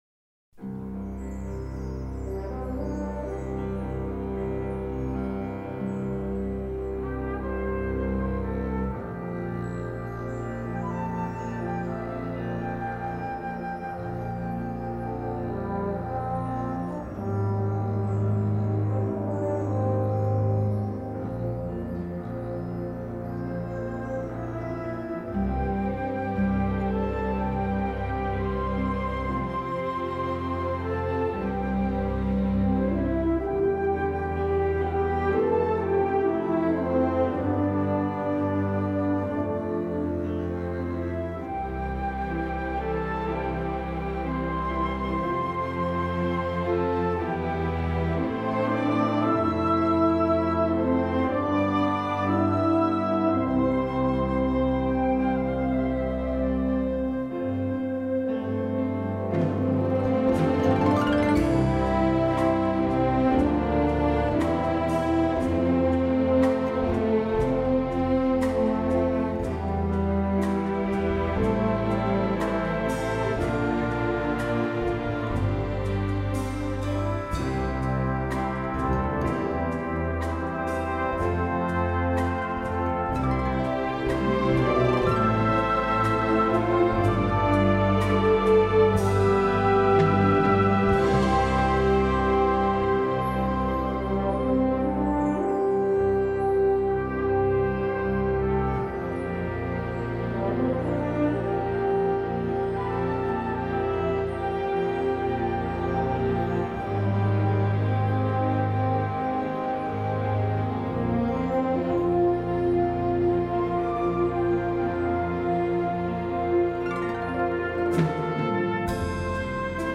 Genre: Gospel & Religious.